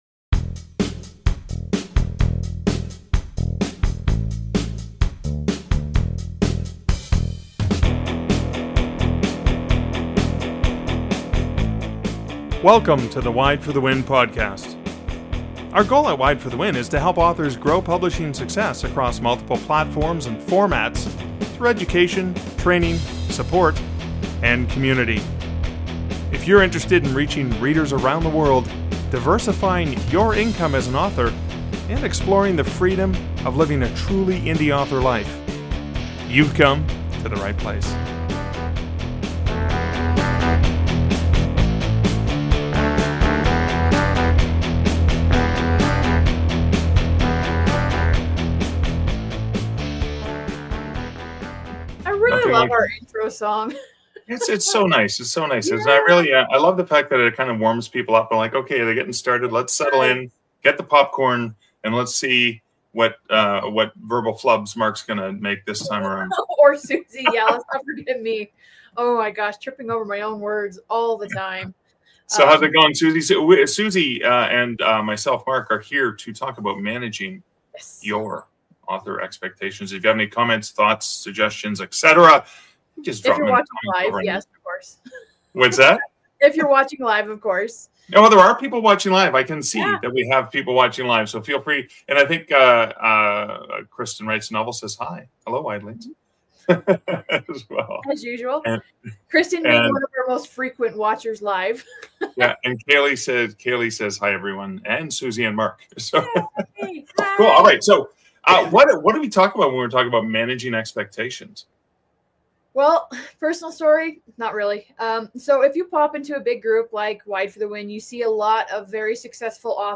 Find out in this round-table discussion.